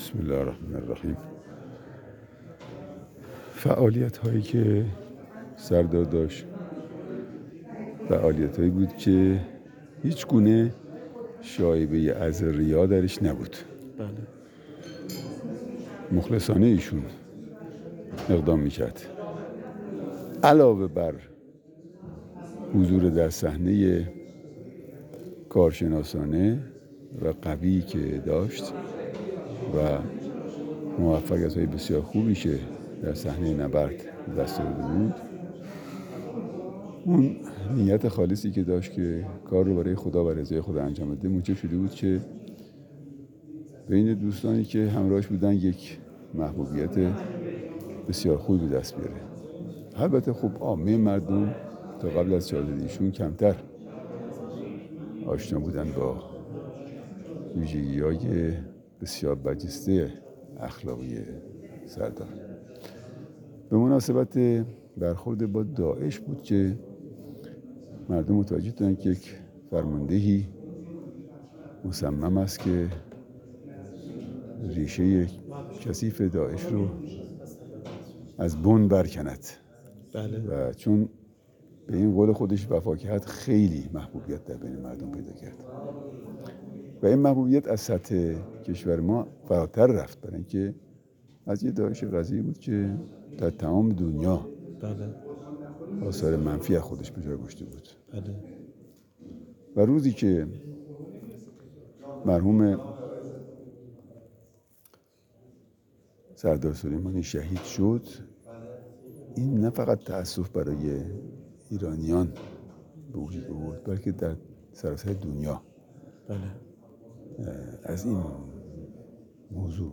میرسلیم در گفت‌وگو با ایکنا بیان کرد: